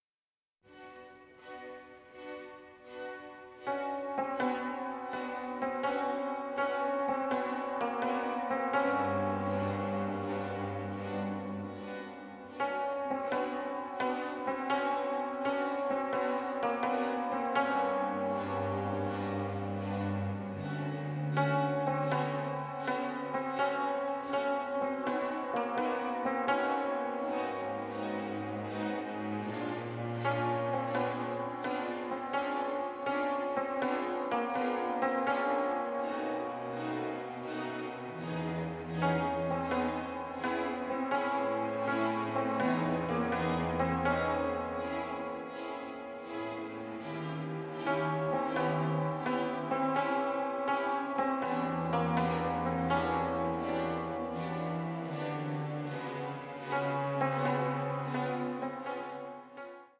in full stereo for the first time.
the melancholic, the macabre and the parodic